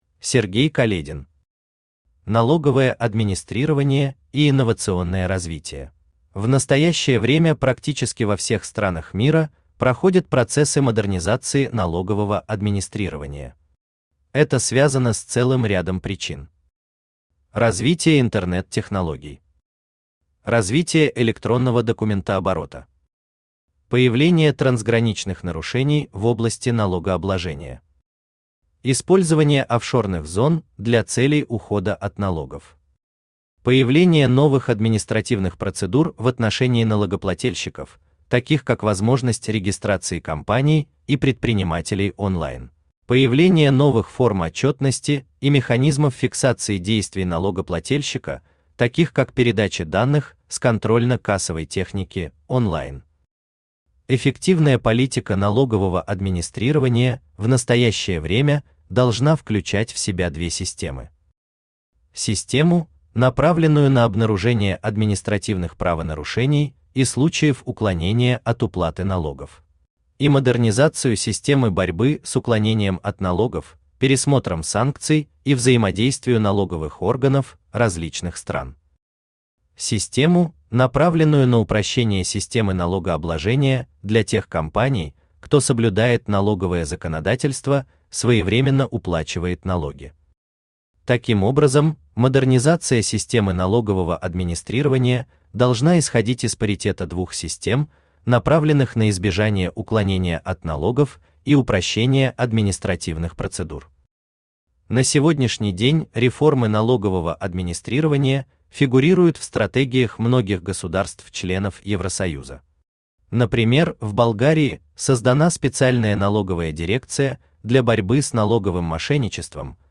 Аудиокнига Налоговое администрирование и инновационное развитие | Библиотека аудиокниг
Aудиокнига Налоговое администрирование и инновационное развитие Автор Сергей Каледин Читает аудиокнигу Авточтец ЛитРес.